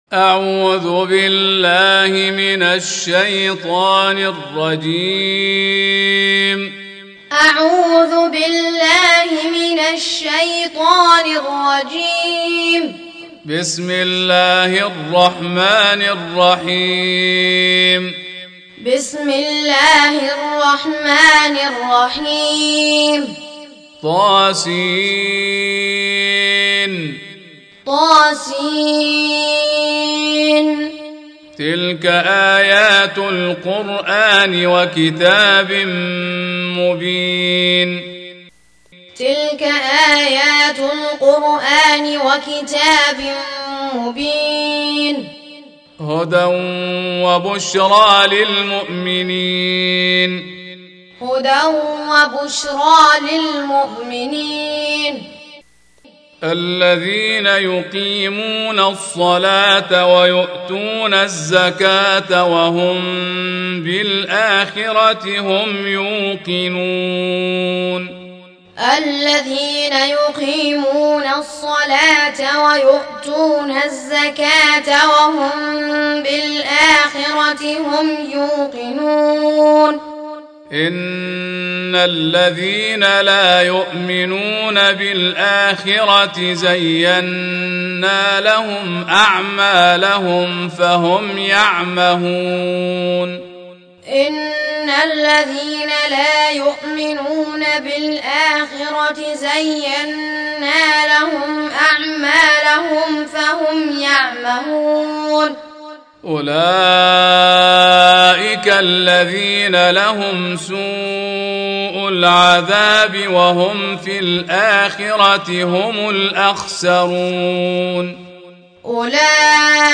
Tutorial Recitation